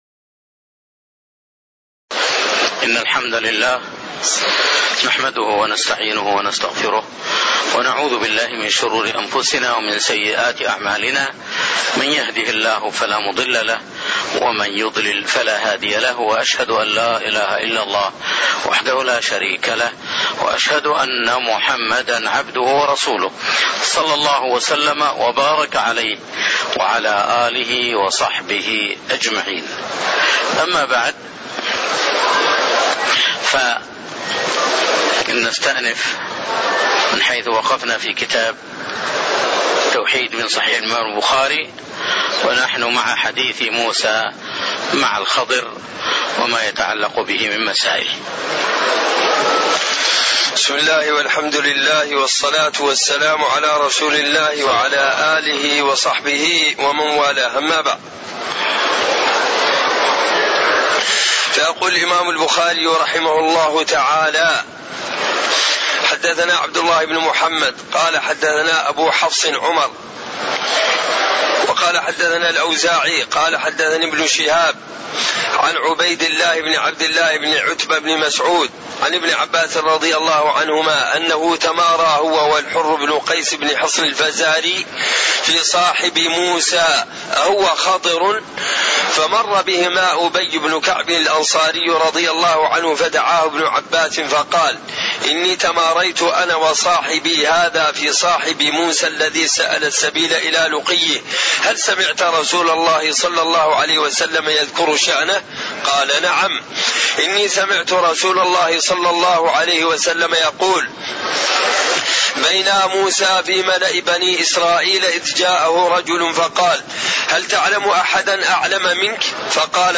تاريخ النشر ٨ ربيع الثاني ١٤٣٥ هـ المكان: المسجد النبوي الشيخ